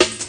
snare30.mp3